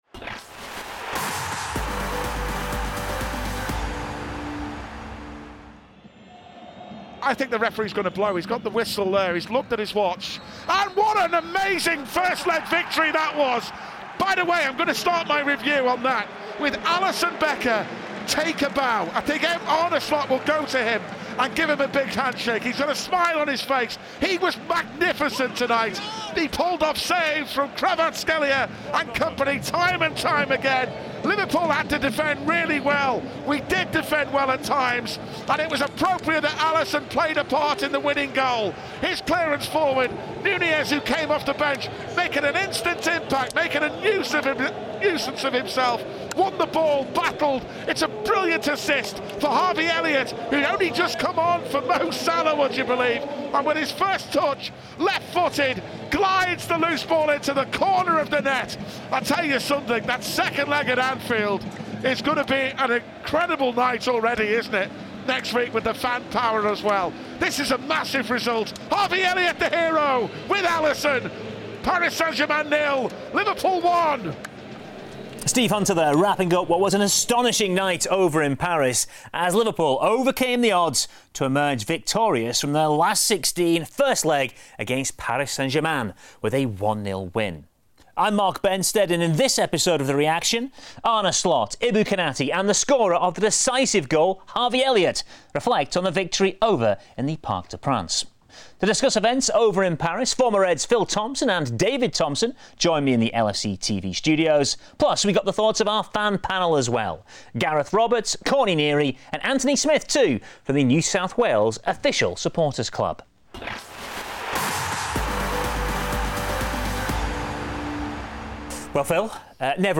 In this episode, Arne Slot, Ibrahima Konaté, and goalscorer Harvey Elliott gave their reactions to Liverpool's hard-fought 1-0 victory in the first leg of their Champions League last-16 tie with Paris Saint-Germain at Parc des Princes.